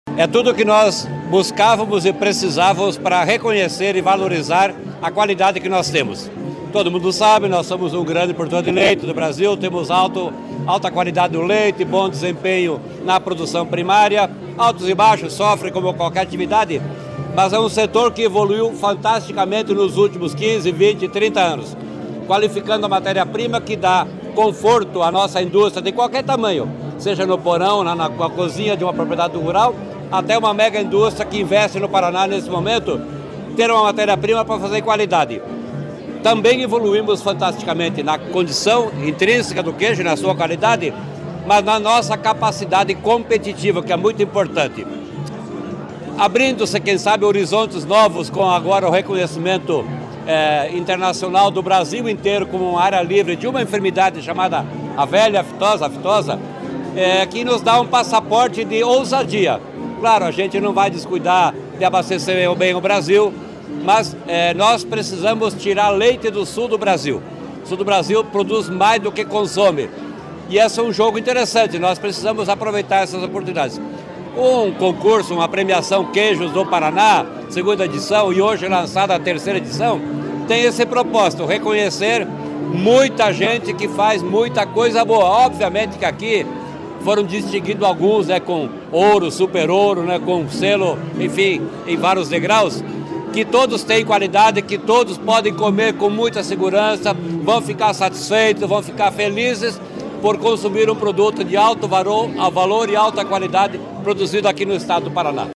Sonora do secretário Estadual da Fazenda, Norberto Ortigara, sobre o Prêmio Queijos do Paraná